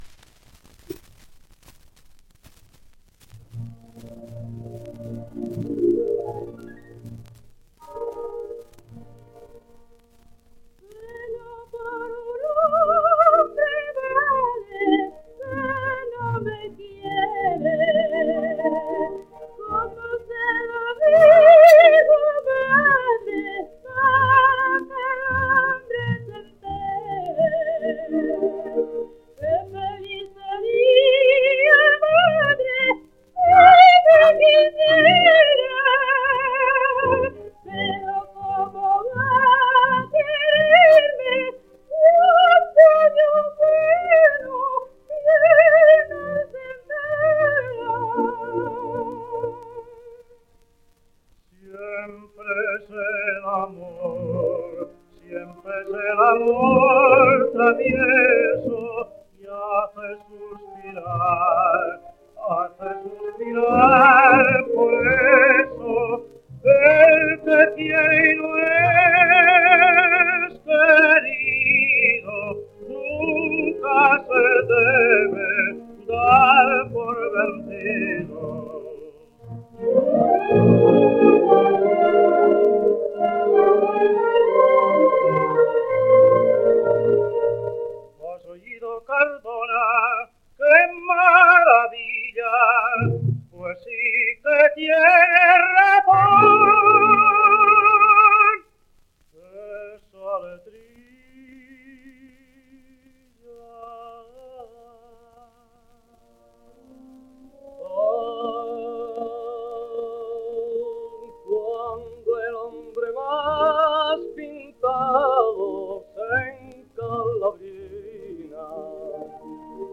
Doña Francisquita. Siempre es el amor? (sonido mejorado)
Editado por: Regal 8 discos : 78 rpm ; 30 cm